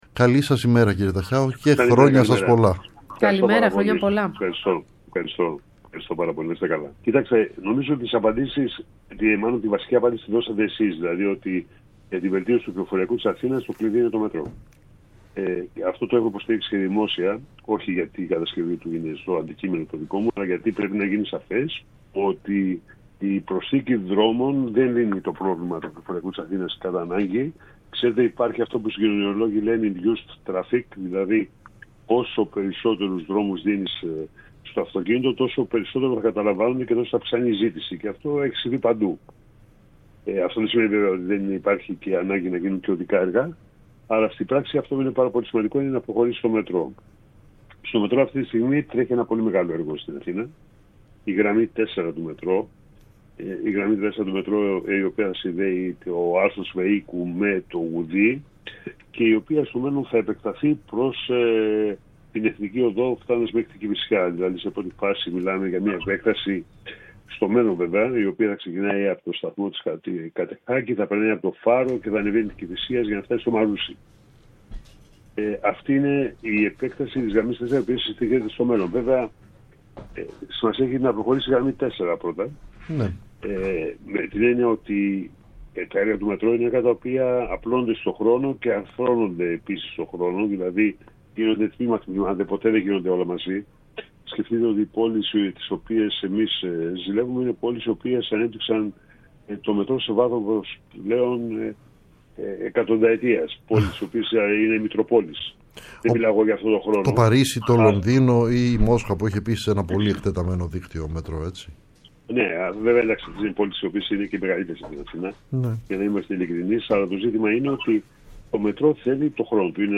Ο Νίκος Ταχιάος, Υφυπουργός Μεταφορών, μίλησε στην εκπομπή “Ραντάρ”